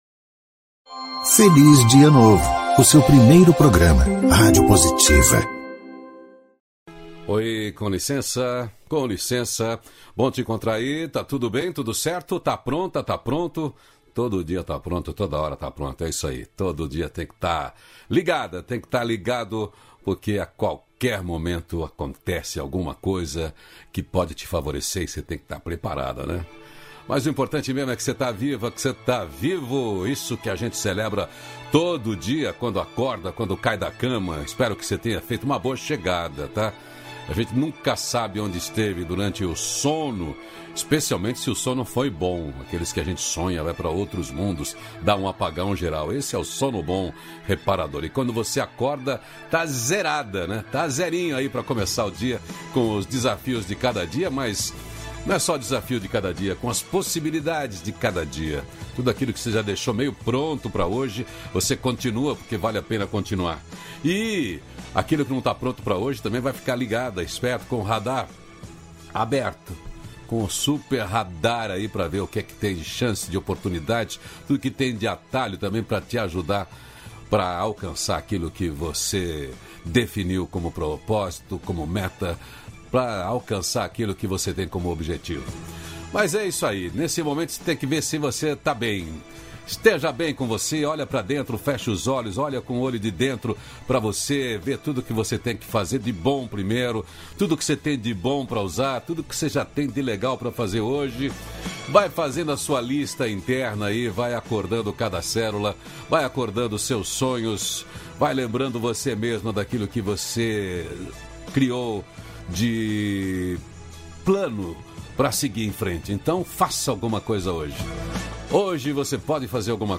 -608FelizDiaNovo-Entrevista.mp3